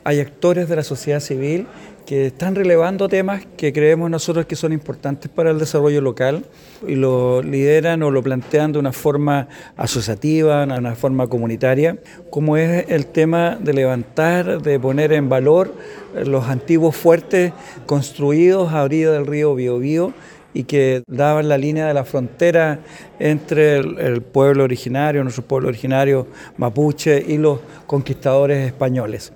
El presidente de la Asociación de Municipalidades Bío Bío Centro y alcalde de Los Ángeles, Esteban Krause, destacó que la idea surja de la misma sociedad civil.